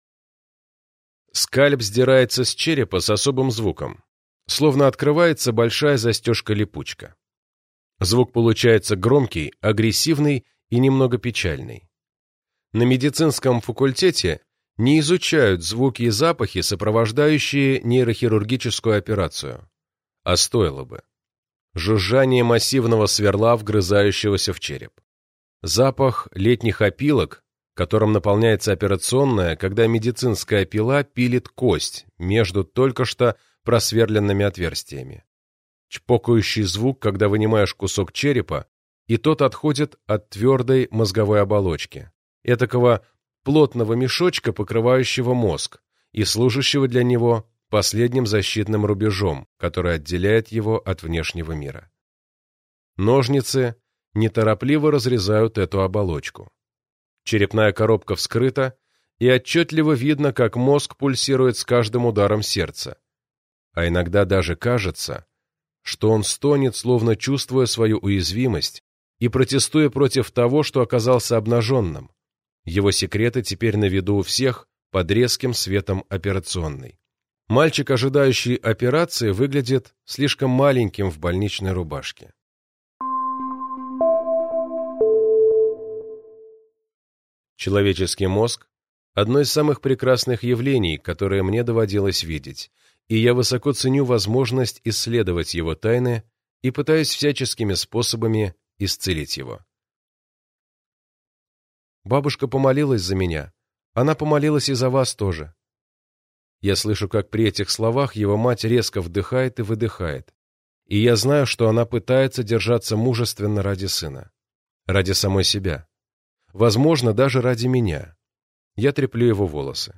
Аудиокнига Компас сердца. История о том, как обычный мальчик стал великим хирургом, разгадав тайны мозга и секреты сердца | Библиотека аудиокниг